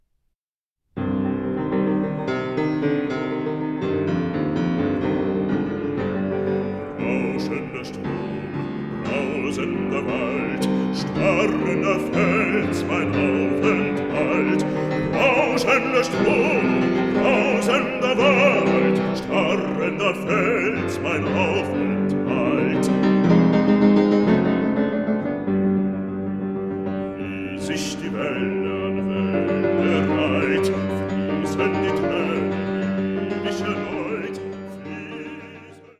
Hammerflügel